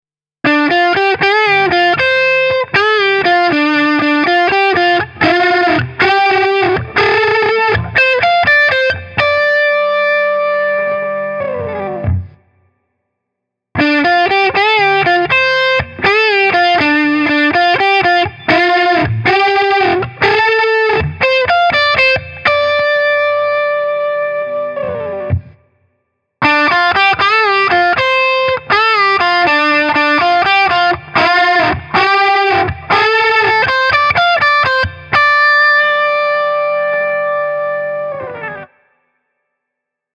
Hagström’s fantastic Custom P-50 pickups add a nice shot of warmth and roundness to the mix, without clouding the Viking’s inherent clarity and dynamics.
The sound is really great, but you have to decide for yourselves how much how much hum and buzz you can stomach: